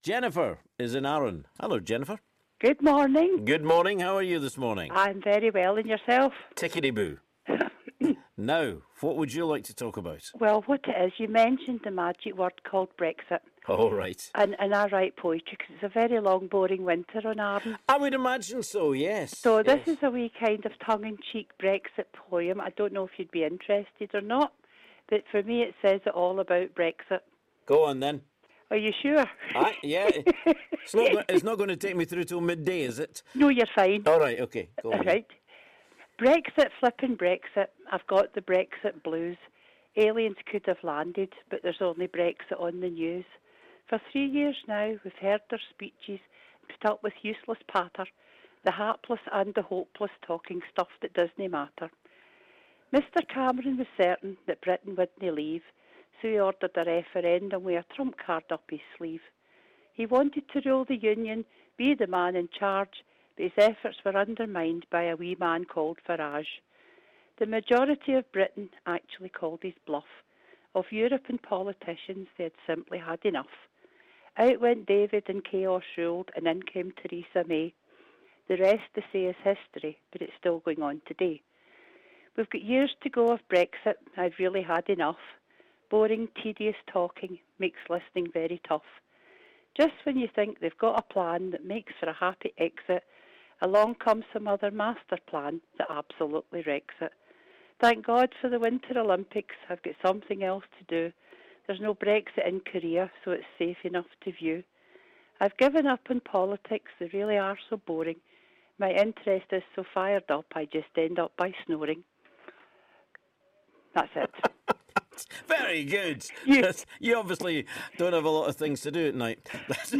Scotland's Talk In every Sunday morning from 10am is your chance to get things off your chest and have your say on the big issues....and you can express them whichever way you like.